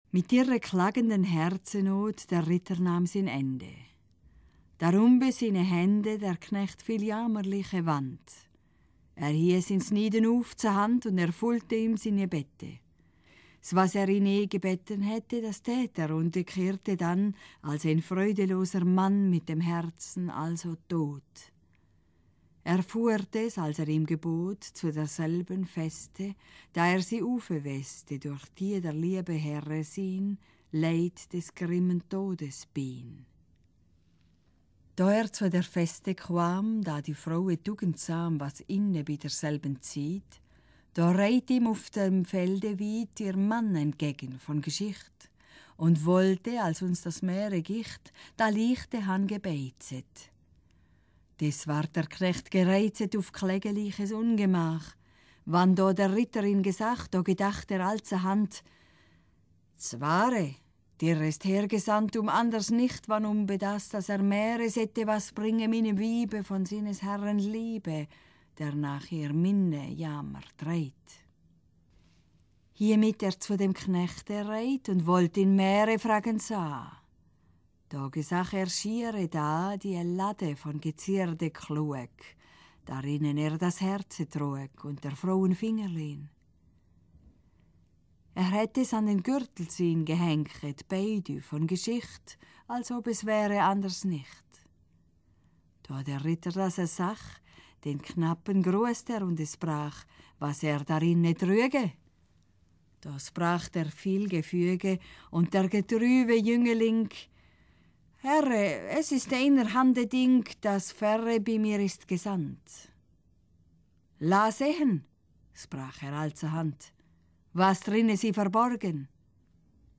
Wir erzählten die Maere neu, zweisprachig und angereichert mit Liedern und Instrumental-Improvisationen.
Im Begleitprogramm der Ausstellung zur Geschichte des Herzens im Museum Appenzell (2004)
Hackbrett